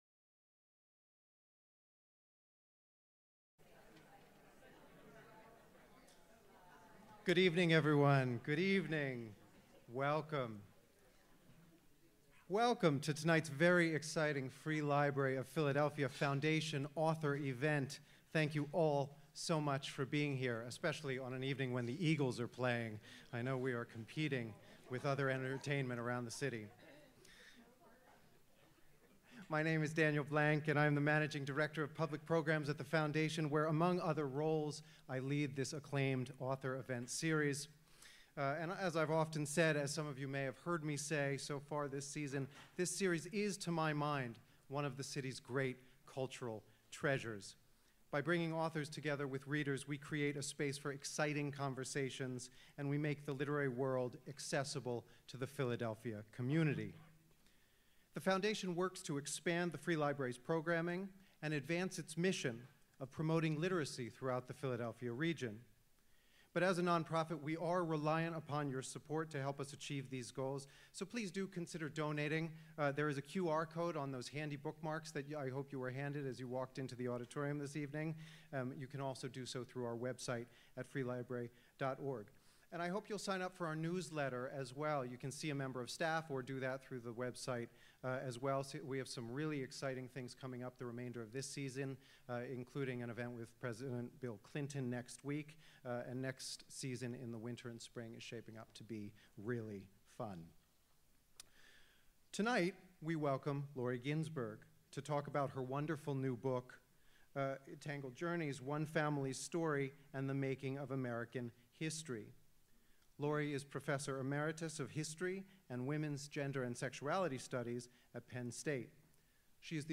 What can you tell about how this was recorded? The Author Events Series presents